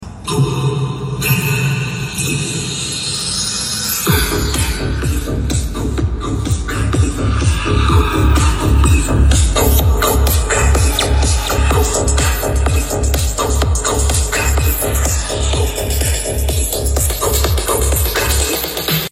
Part 96｜YEAHBOX speaker Sonic Boom